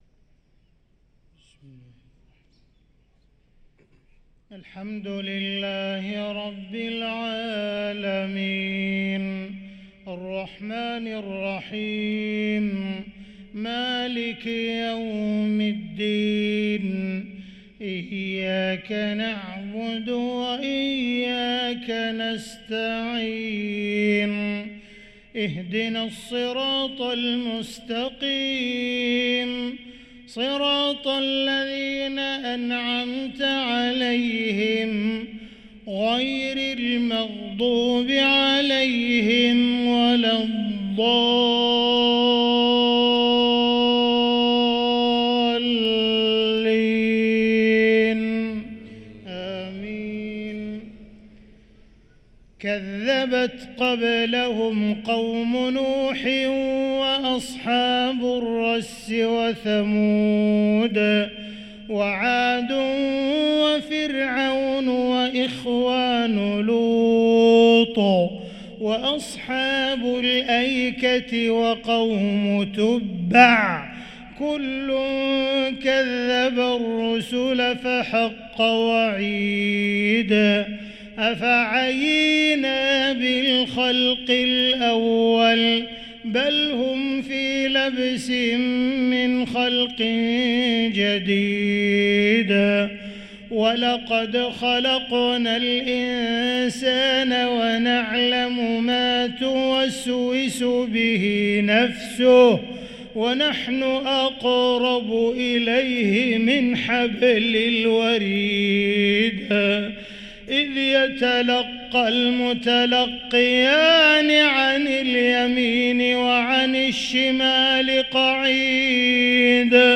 صلاة الفجر للقارئ عبدالرحمن السديس 16 ربيع الأول 1445 هـ
تِلَاوَات الْحَرَمَيْن .